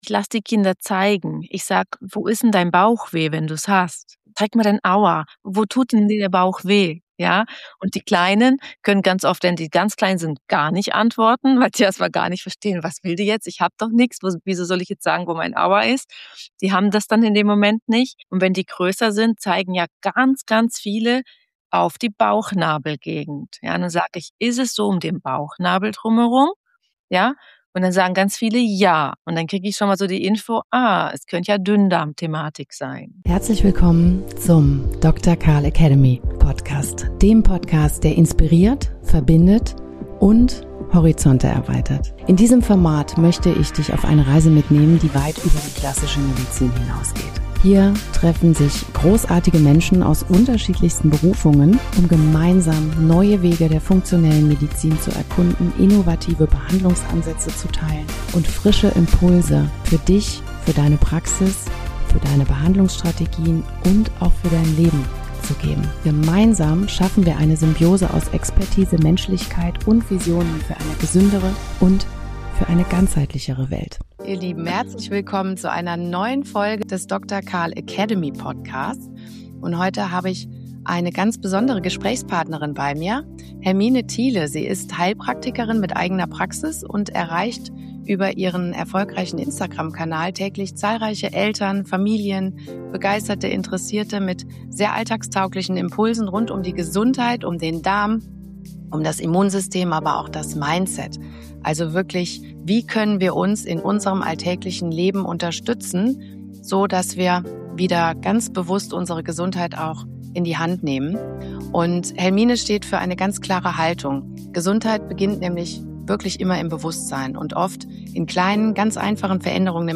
Dieses Gespräch beleuchtet die Bedeutung einer ganzheitlichen Sichtweise auf die Gesundheit von Kindern, die Bedeutung der Darmgesundheit, Stressregulation in Familien sowie praktische Ansätze für Eltern.